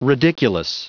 Prononciation du mot ridiculous en anglais (fichier audio)
Prononciation du mot : ridiculous